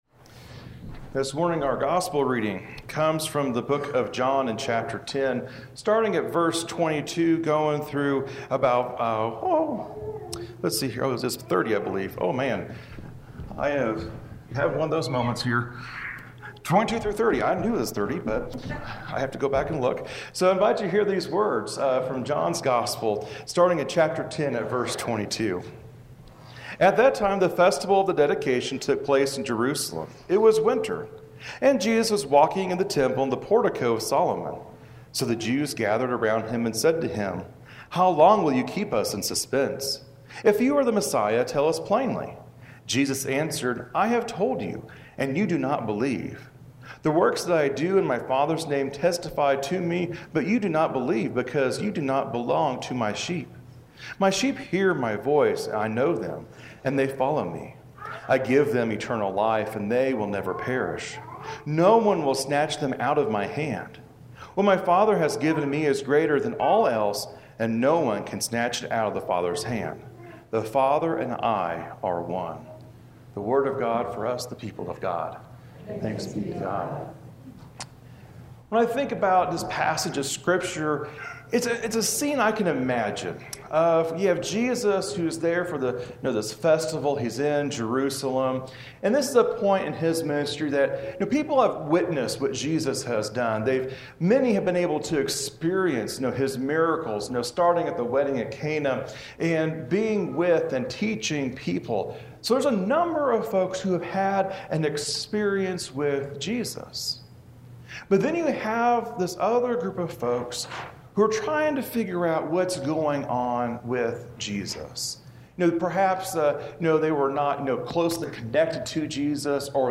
This week we take a look at John 10:22-30, and examine our experience and connection with Christ. This recording is from McClave UMC.
Sunday-Sermon-May-12.mp3